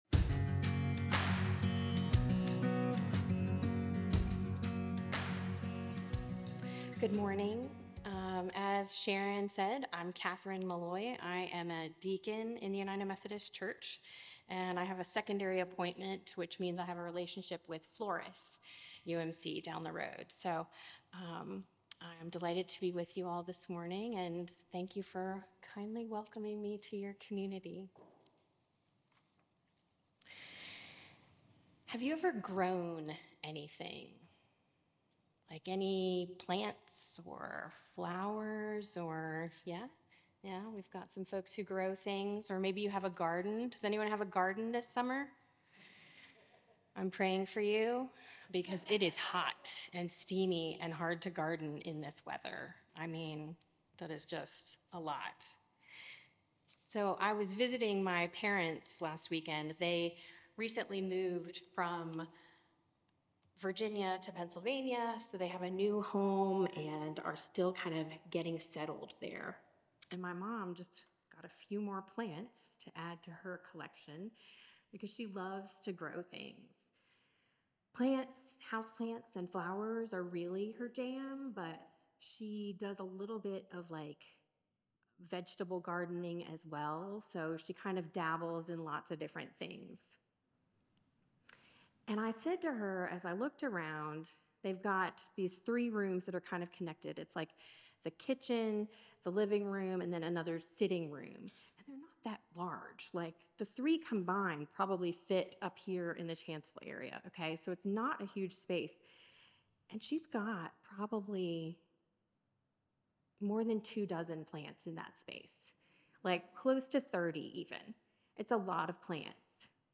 This morning we are joined in worship by a guest preacher